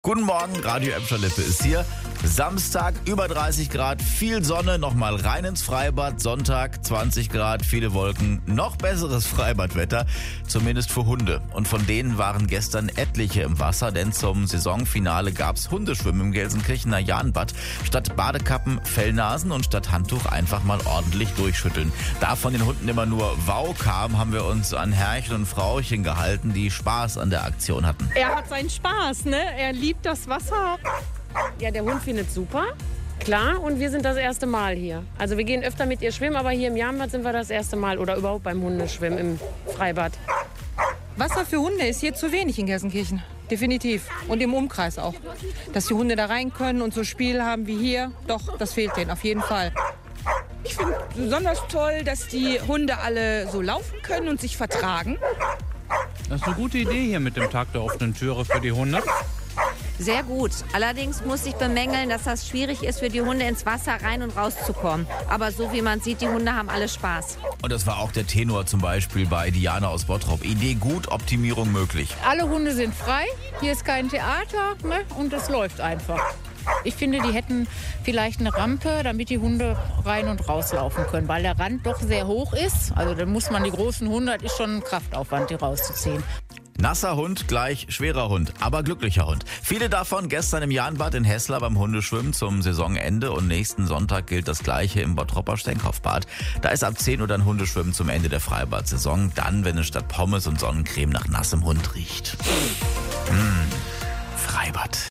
Und von denen waren gestern etliche im Wasser, denn zum Saisonfinale gab es Hundeschwimmen im Gelsenkirchener Jahnbad.
Da von den Hunden immer nur “wau” kam, haben wir uns an Herrchen und Frauchen gehalten - die Spaß an der Aktion hatten.
hundeschwimmen-jahnbad.mp3